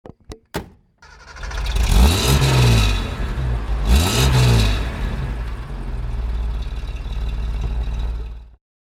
Engine sounds of Datsun vehicles (random selection)